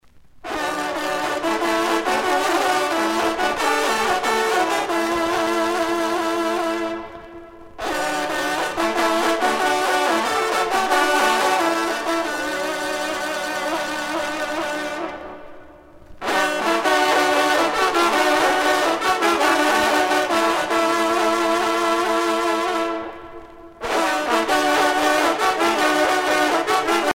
sonnerie vénerie - fanfare d'équipage
Pièce musicale éditée